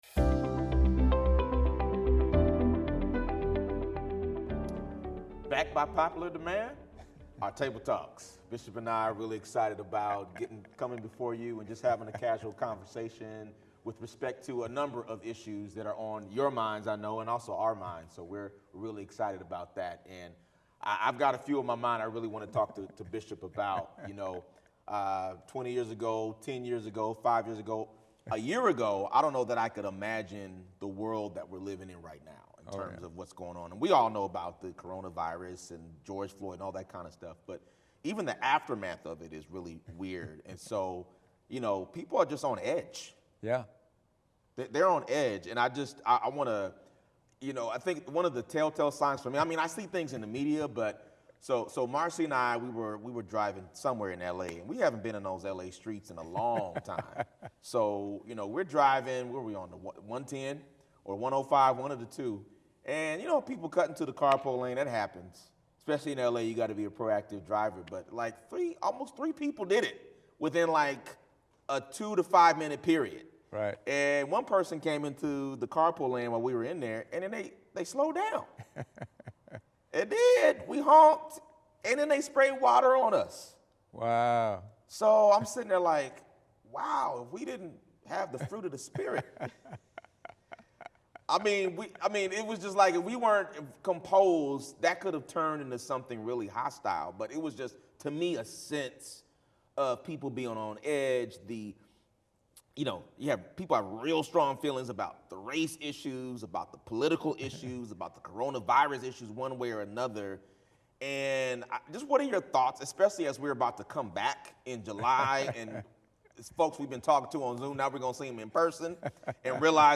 WATCH THE SERMON ONLY Listen to Our Worship Playlist FULL SERVICES SUNDAY SERMONS View Sermon Notes SONGS WE SING IN SERVICE LISTEN TO THE FULL TRACKS ON SPOTIFY LISTEN TO THE FULL TRACKS ON YOUTUBE